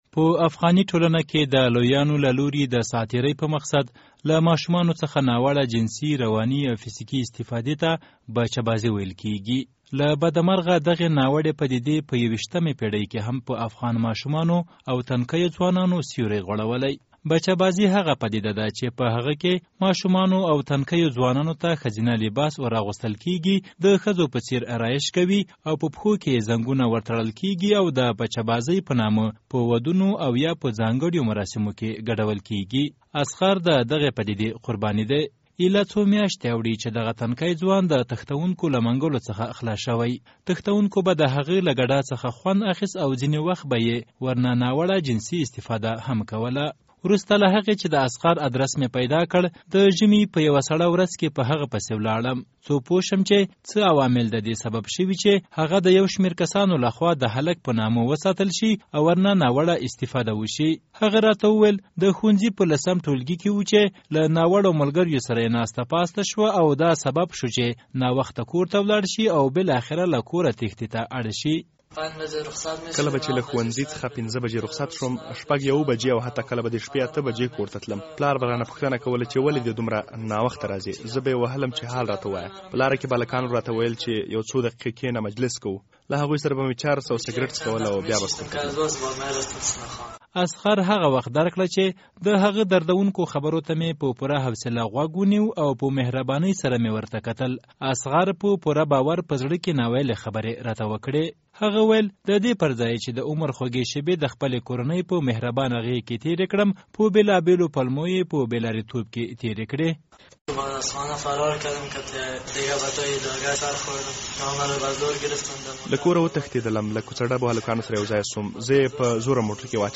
د بچه بازی په اړه څیړنیز راپور